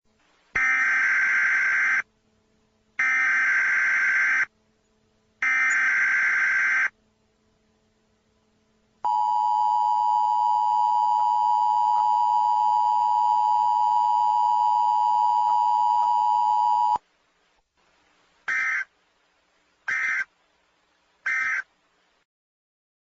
nws_alert_tone.mp3